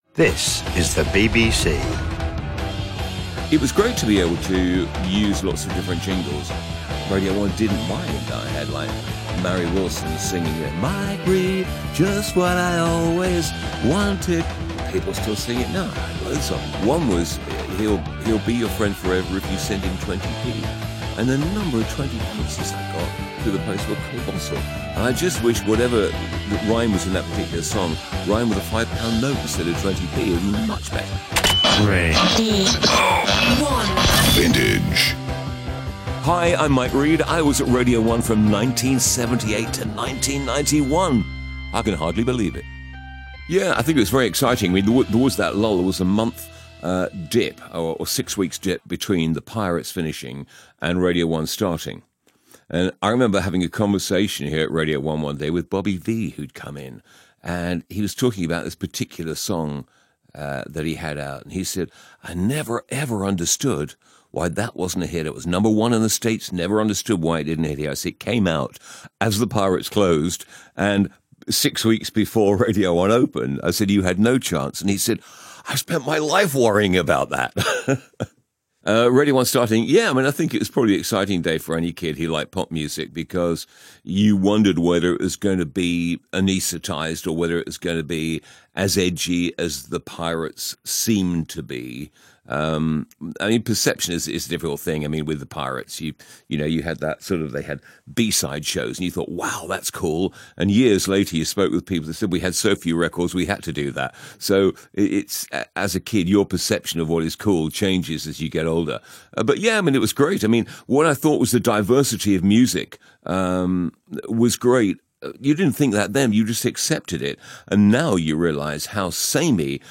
mike read radio one vintage interview